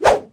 handswing5.ogg